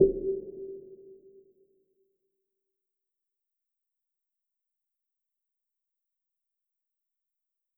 Snare (Lose You).wav